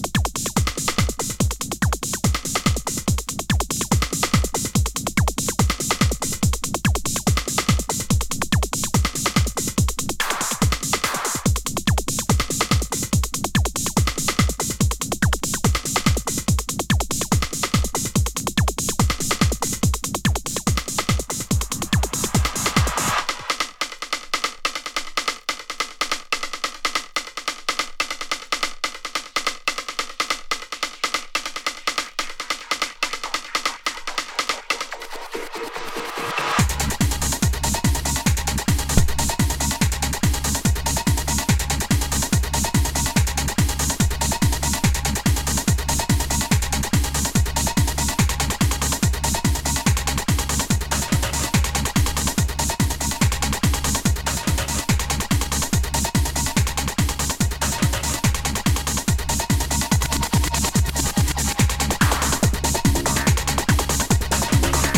テクノ
シンセフレーズがキケン過ぎる